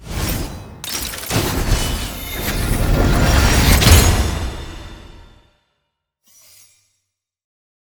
sfx-tier-crystals-promotion-to-bronze.ogg